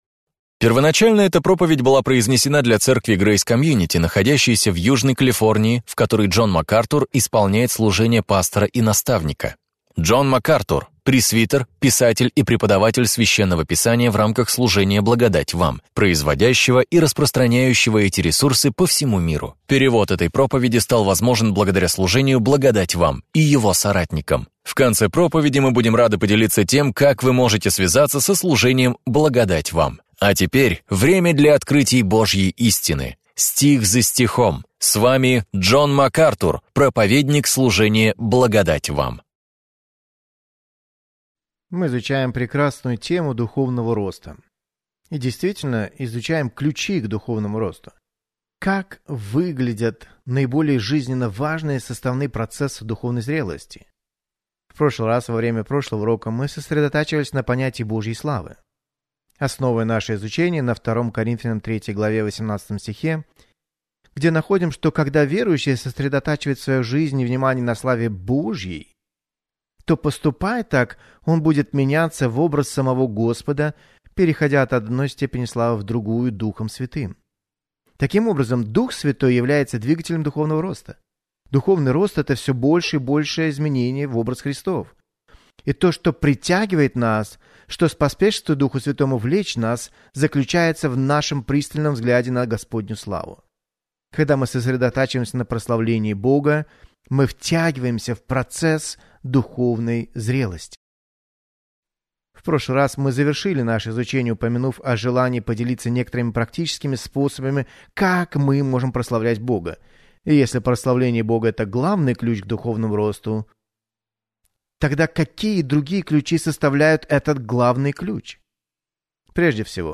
Проповеди МакАртура